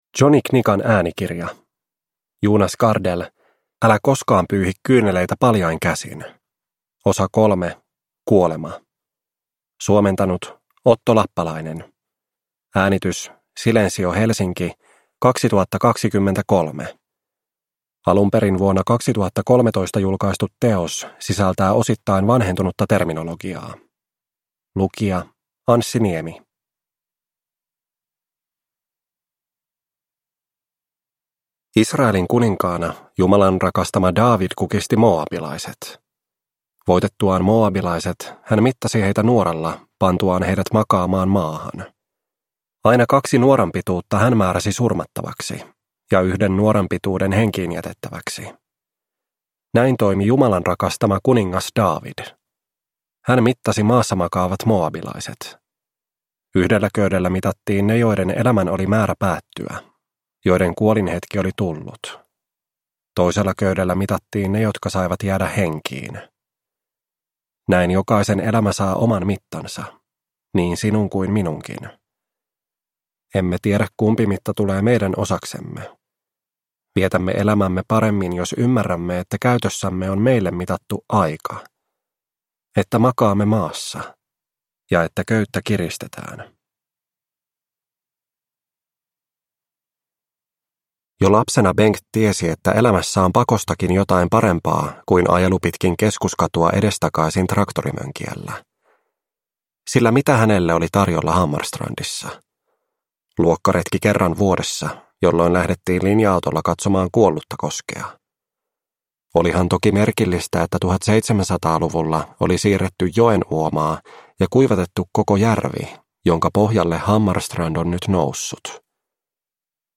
Älä koskaan pyyhi kyyneleitä paljain käsin – 3. Kuolema – Ljudbok